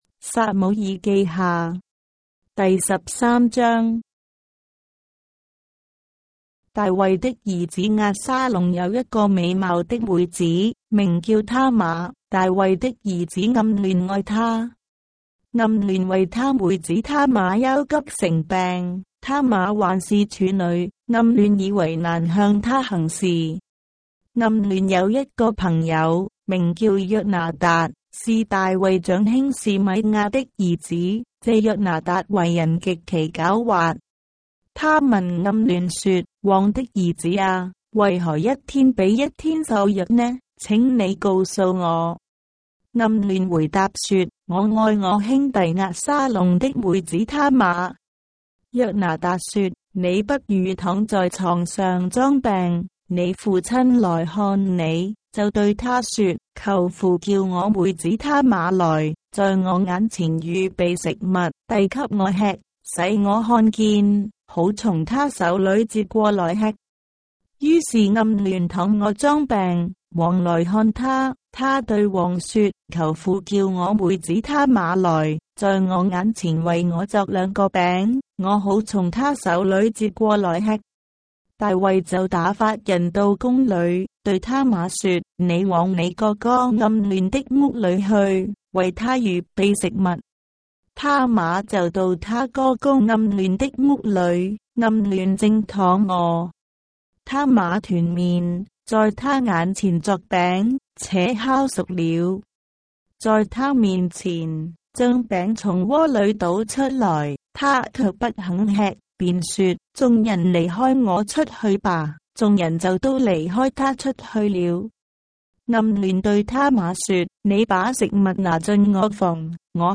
章的聖經在中國的語言，音頻旁白- 2 Samuel, chapter 13 of the Holy Bible in Traditional Chinese